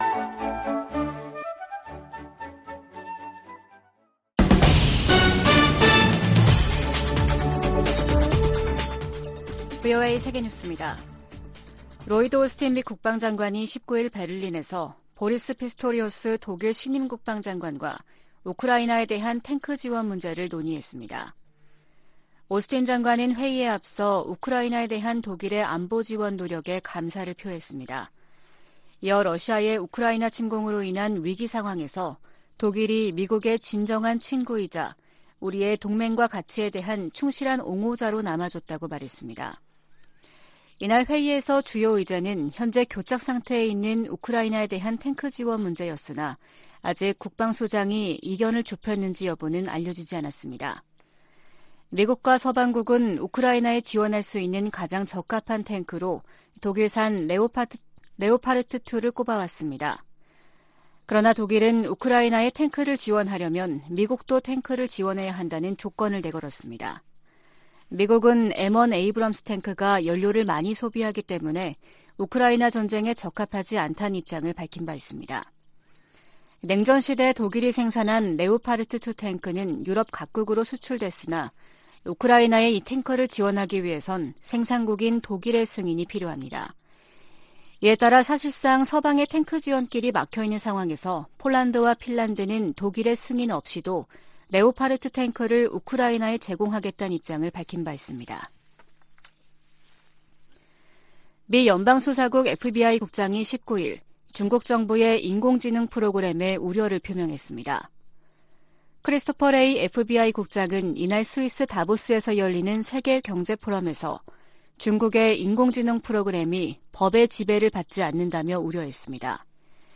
VOA 한국어 아침 뉴스 프로그램 '워싱턴 뉴스 광장' 2023년 1월 20일 방송입니다. 북한이 핵보유국을 자처해도 미국의 한반도 비핵화 목표에는 변함이 없다고 국무부가 밝혔습니다. 김정은 국무위원장이 불참한 가운데 열린 북한 최고인민회의는 경제난 타개를 위한 대책은 보이지 않고 사상 통제를 강화하는 조치들을 두드러졌다는 분석이 나오고 있습니다.